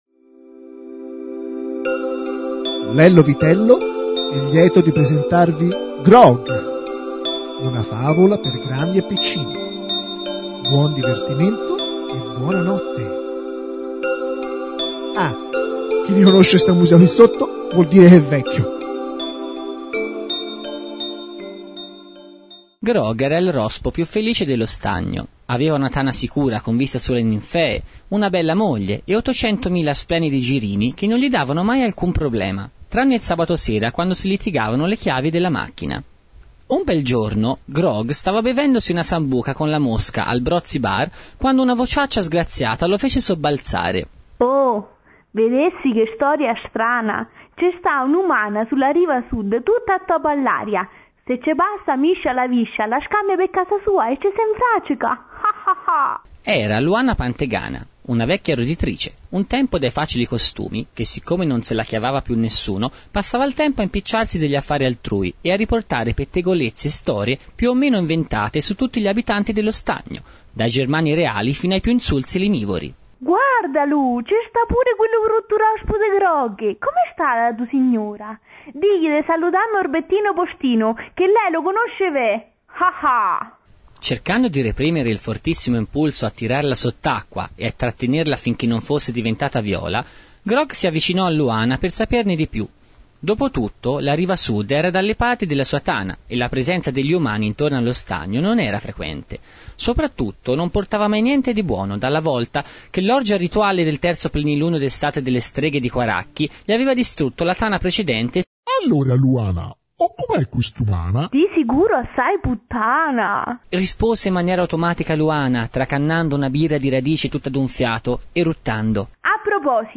Una fiaba sonora, come quelle che ascoltavo da bambino sui 45 giri nel mangiadischi.